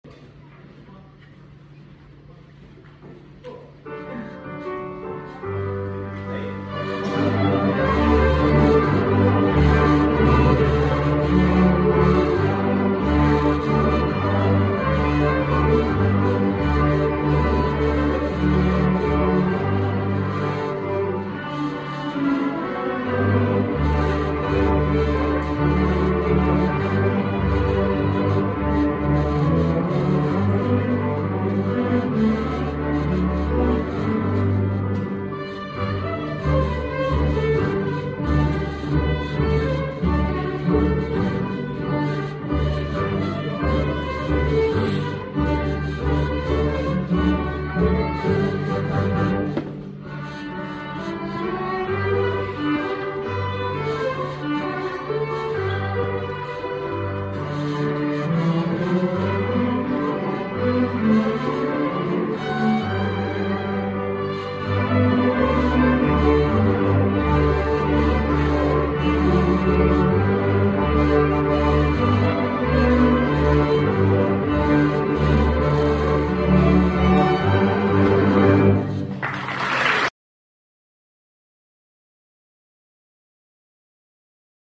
Mango Walk | Larch String Orchestra